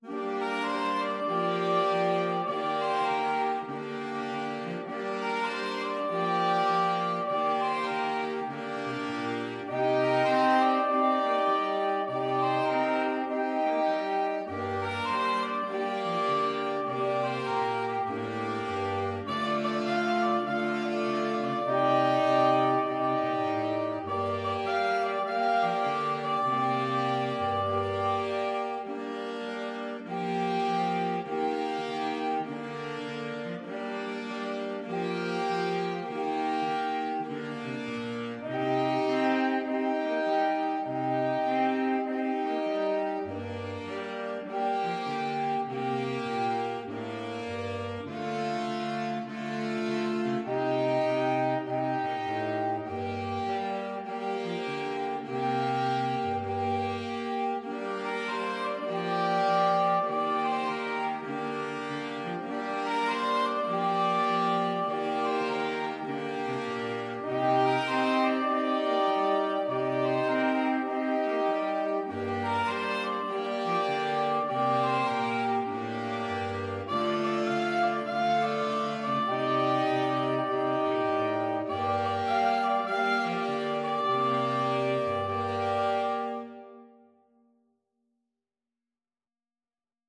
A simple blues tune with an improvisatory section
4/4 (View more 4/4 Music)
With a swing!
Saxophone Quartet  (View more Easy Saxophone Quartet Music)